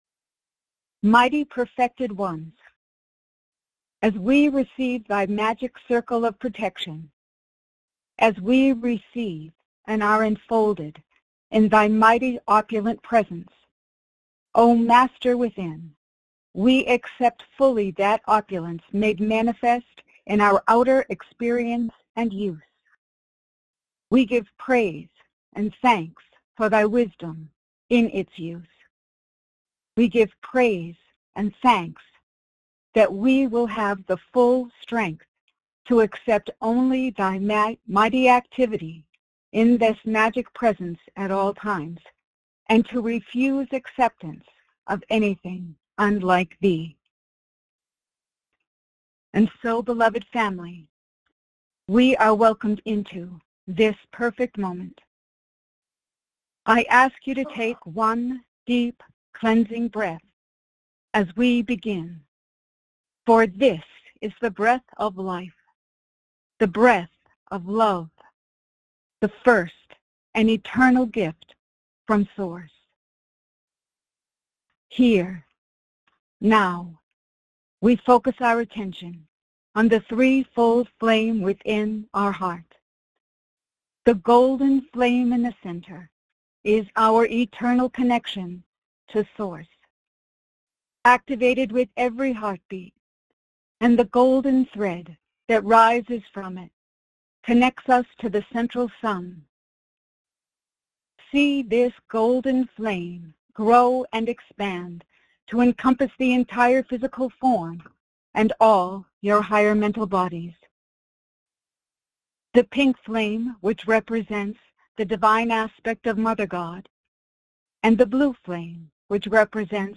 Follow along in group meditation with master Saint Germain)